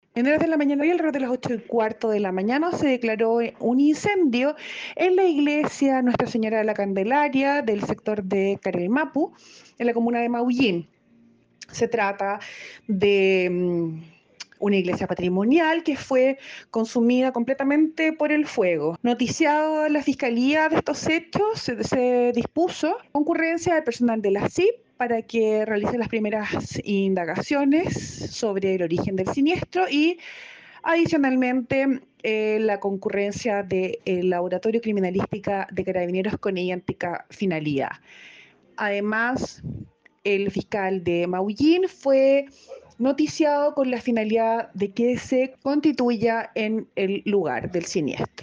La fiscal de Puerto Montt Leyla Chaín confirmó el inicio de las primeras gestiones para indagar acerca del origen del incendio, con los respectivos encargos para las diligencias a las unidades especializadas de Carabineros.